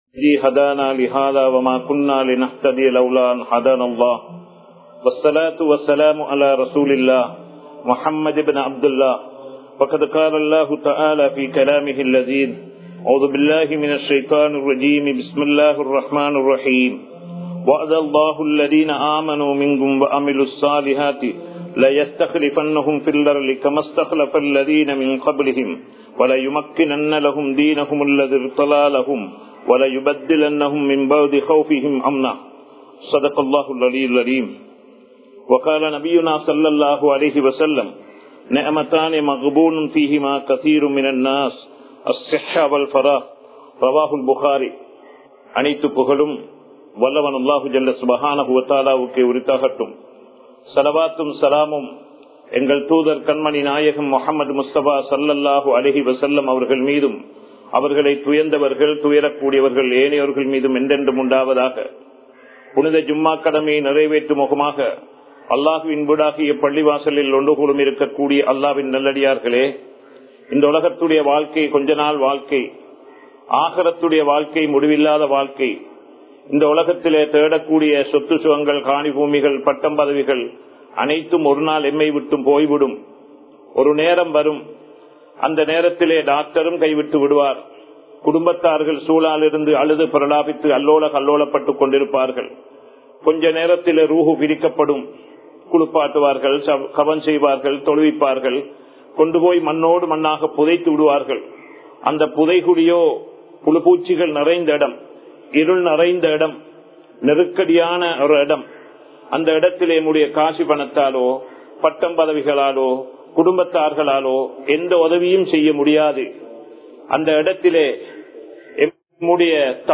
எம் தாய் நாட்டை நேசியுங்கள் | Audio Bayans | All Ceylon Muslim Youth Community | Addalaichenai
Colombo 03, Kollupitty Jumua Masjith